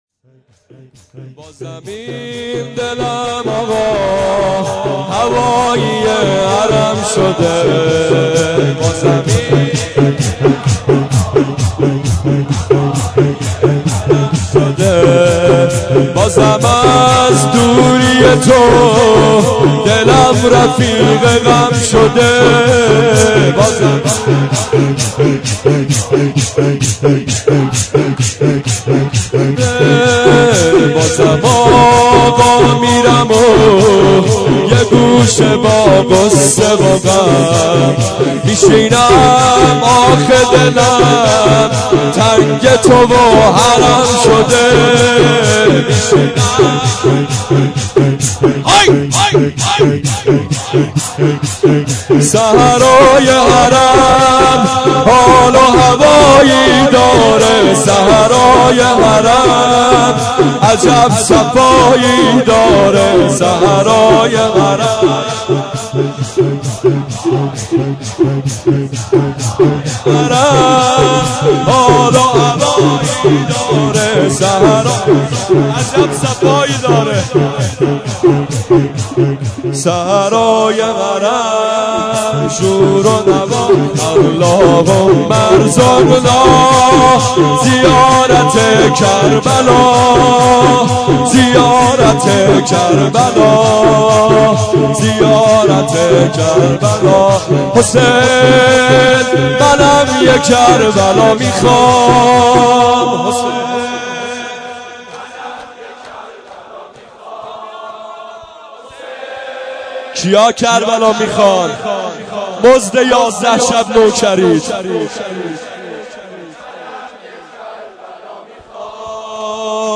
مداحی شور بازم این دلم آقا هوایی حرم شده
شور